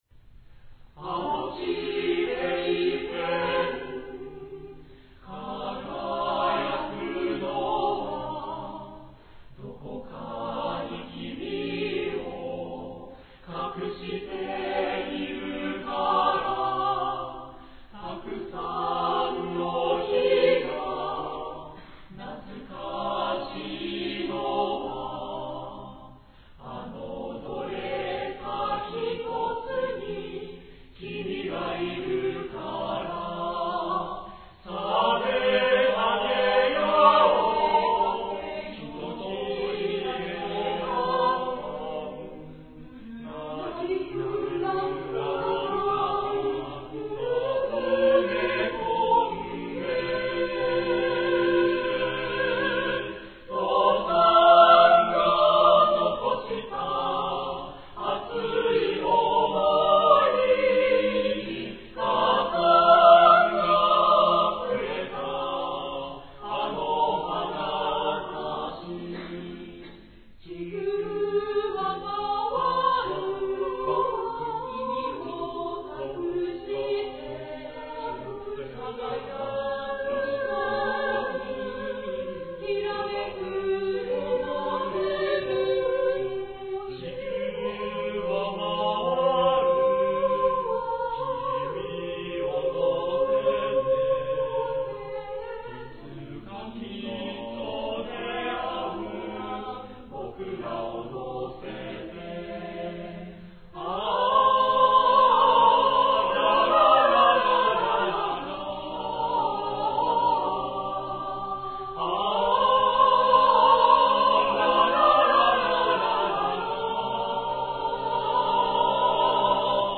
第４７回愛知県合唱祭（愛知県勤労会館）に出演しました。